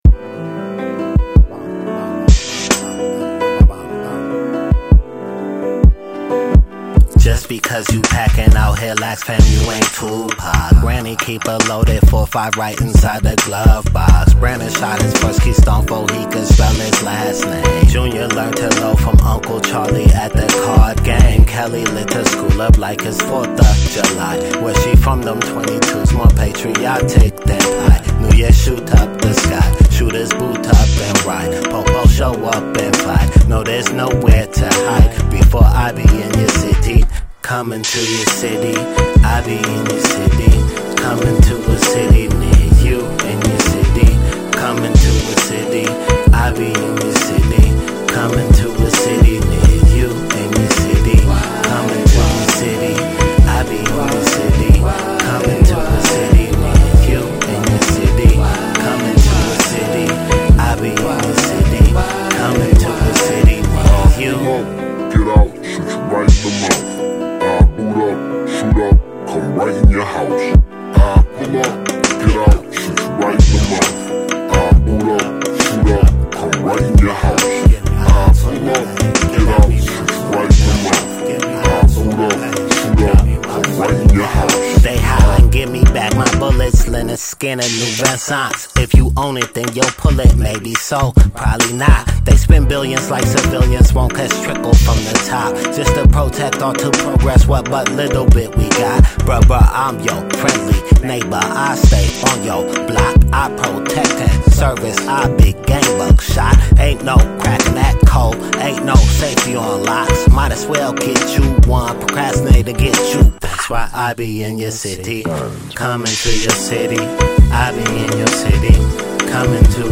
Below are some of my favorite hip-hop highlights of 2019.